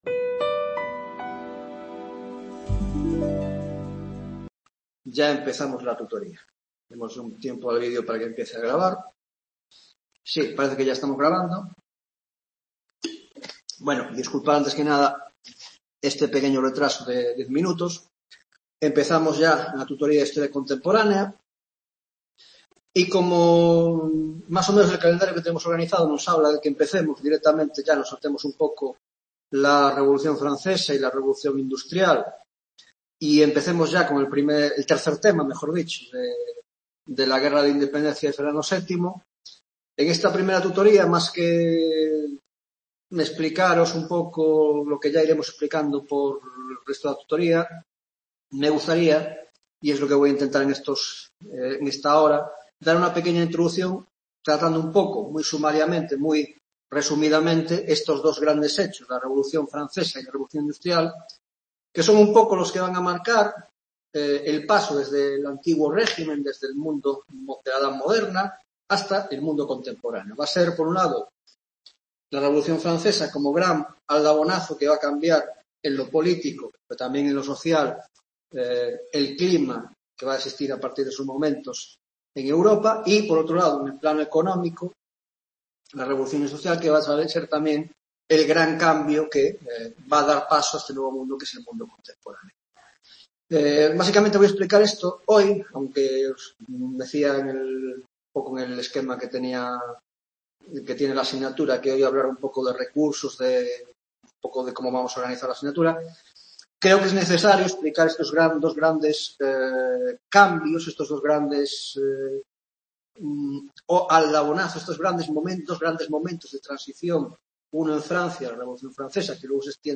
1ª Tutoría de Historia Contemporánea